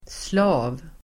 Uttal: [sla:v]